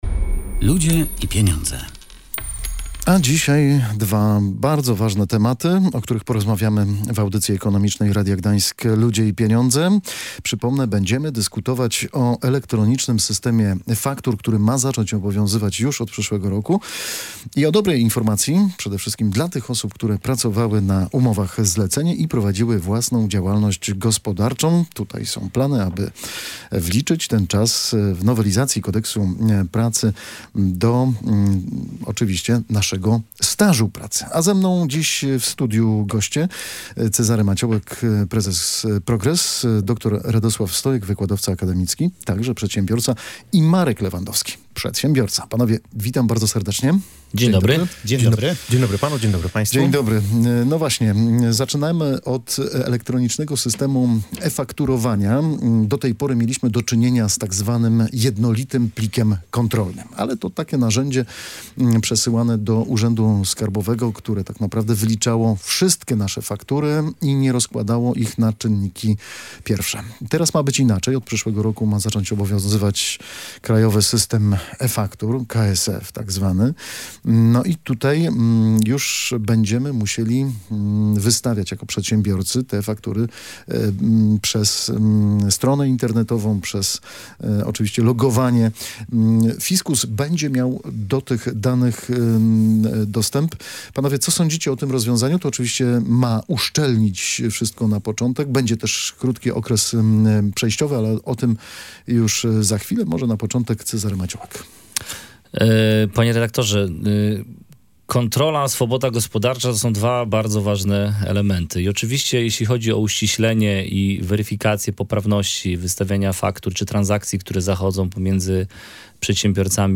Między innymi na ten temat rozmawialiśmy w audycji „Ludzie i Pieniądze”.